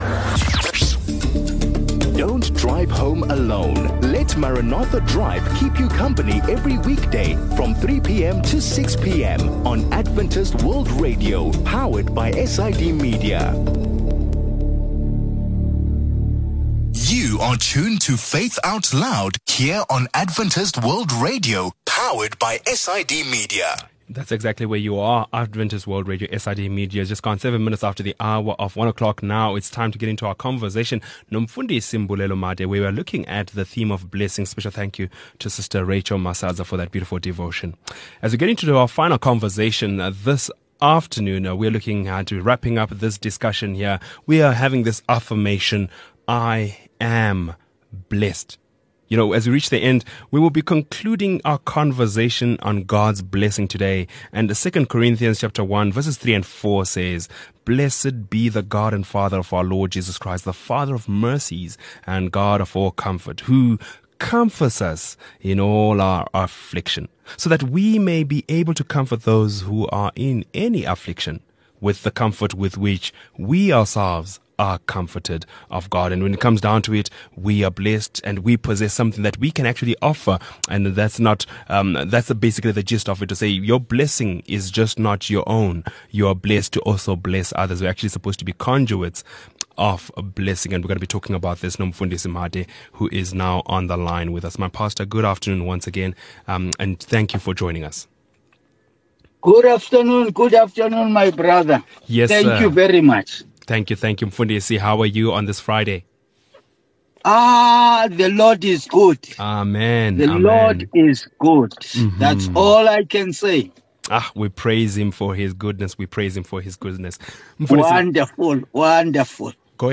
We have reached the end of the week, and we will be concluding our conversation on God’s blessings.